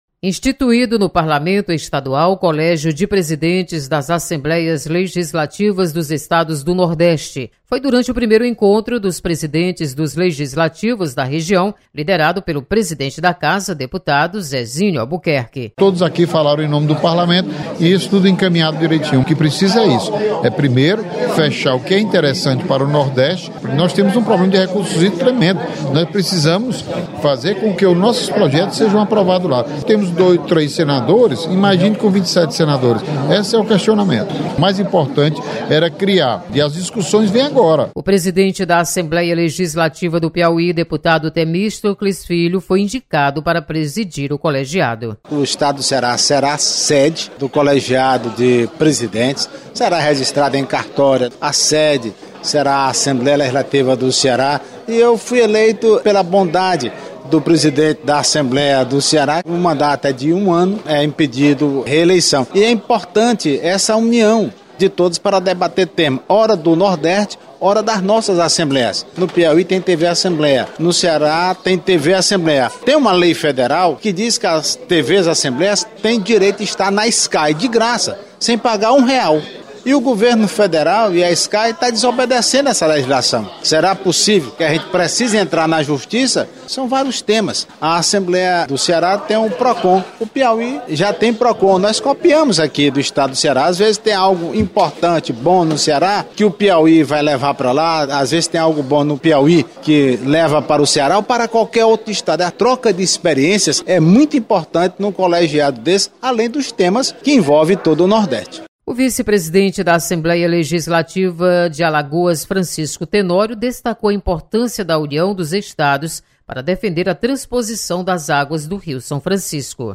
Deputados comentam criação de Colégio de Presidentes das Assembleias Legislativas do Nordeste.